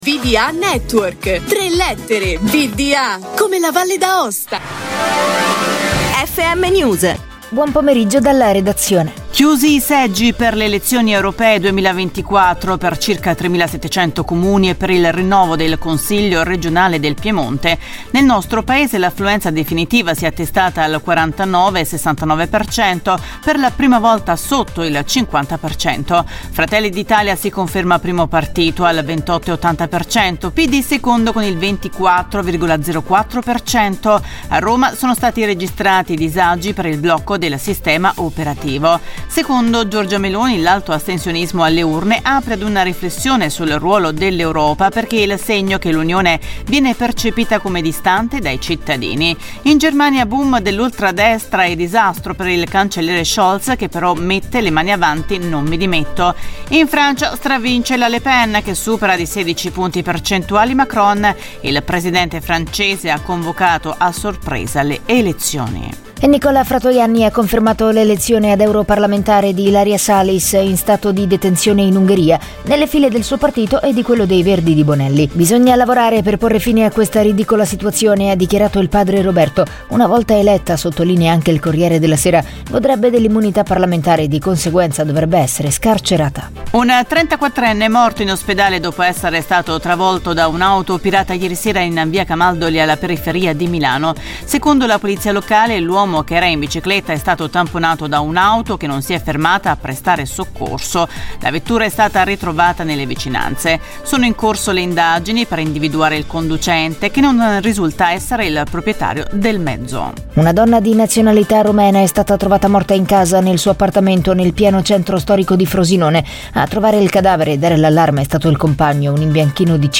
Notiziario Nazionale